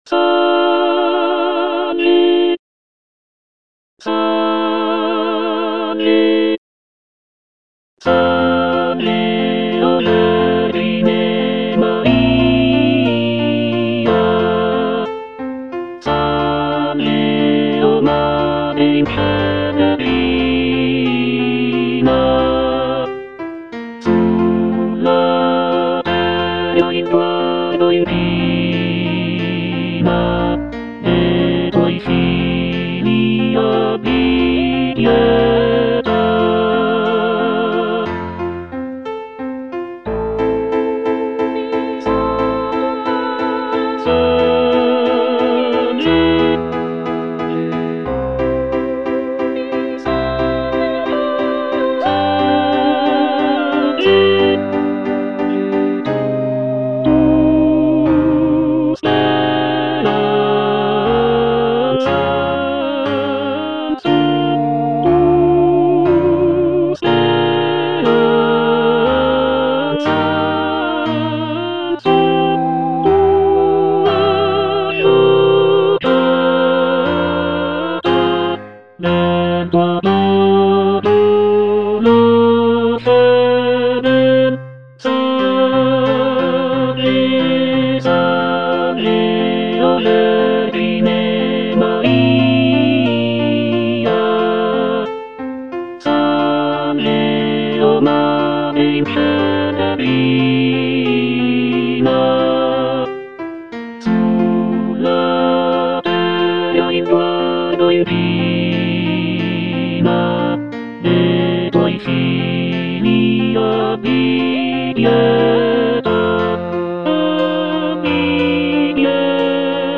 G. ROSSINI - SALVE O VERGINE MARIA (EDITION 2) Tenor (Emphasised voice and other voices) Ads stop: auto-stop